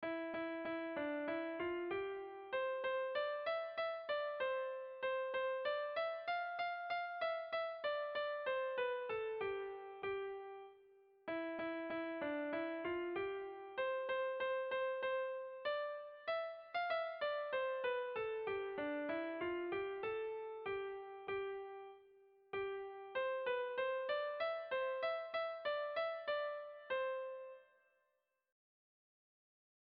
Dantzakoa
ABA2DE